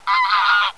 goose.wav